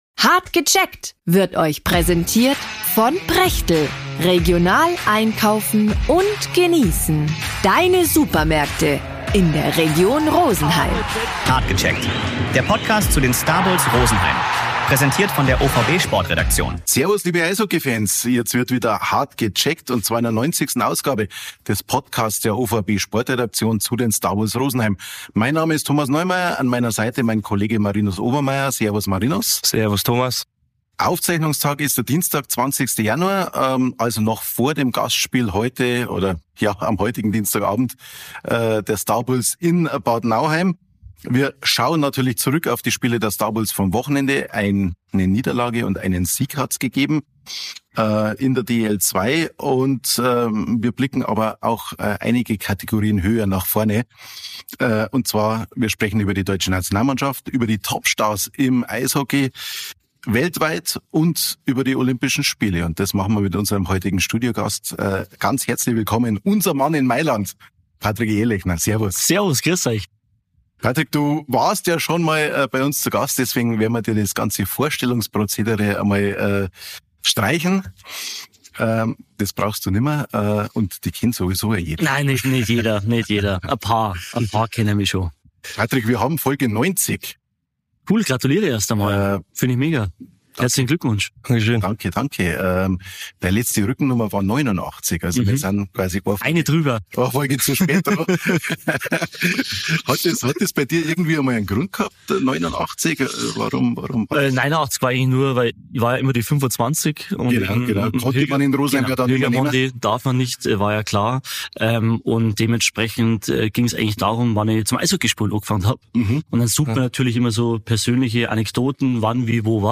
„Hart gecheckt“ – der OVB-Podcast zu den Starbulls Rosenheim sendet diese Woche die 90. Folge und hat dafür einen besonderen Gast.